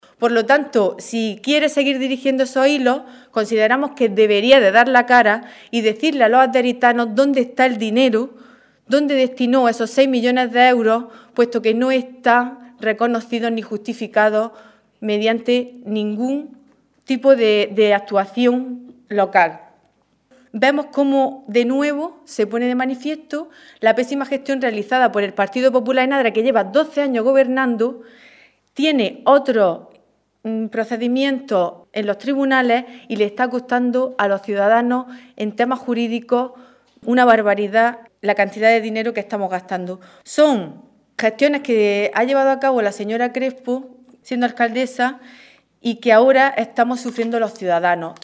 Rueda de prensa que ha ofrecido la portavoz socialista en el Ayuntamiento de Adra y candidata a la Alcaldía, Teresa Piqueras